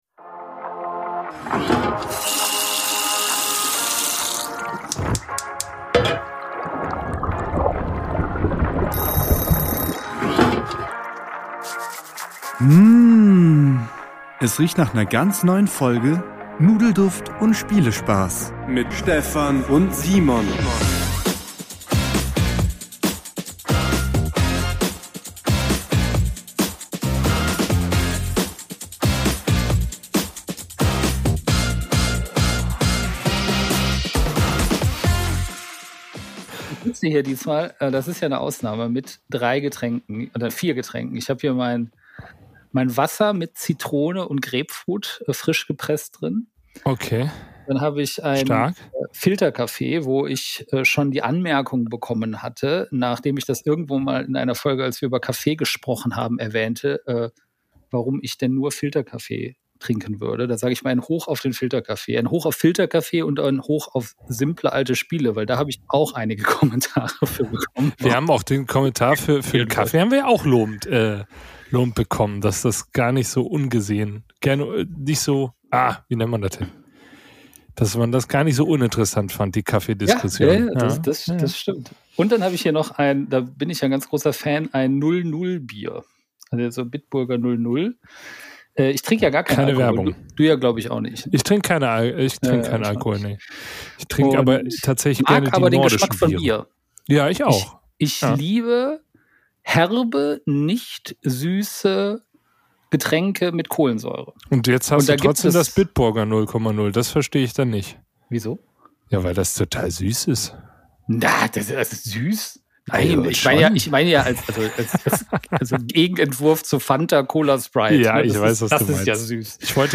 1 Specialfolge aus dem Auto: Wir lassen das Brettspielwochenende Revue passieren 2:49:52